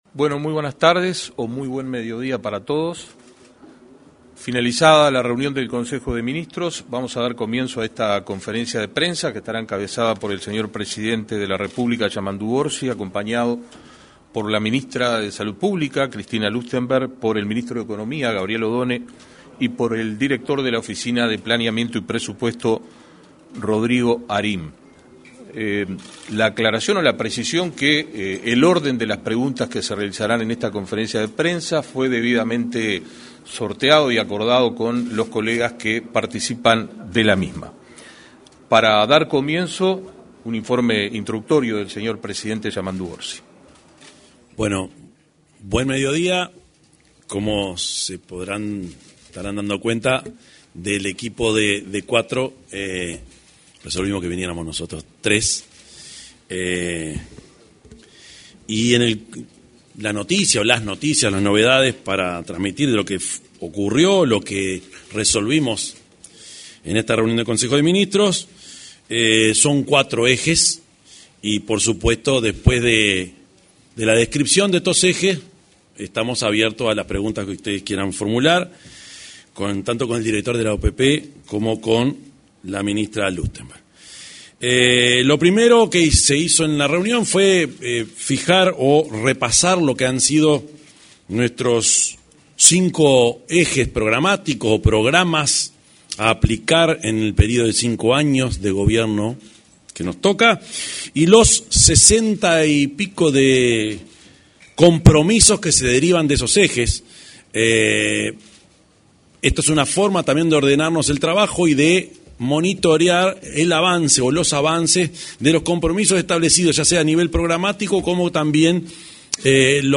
Conferencia de prensa tras el Consejo de Ministros
Este martes 25 se realizó una conferencia de prensa, luego de finalizado el Consejo de Ministros.
En la oportunidad, se expresó el presidente de la República, profesor Yamandú Orsi; la ministra de Salud Pública, Cristina Lustemberg, y el director de la Oficina de Planeamiento y Presupuesto, Rodrigo Arim.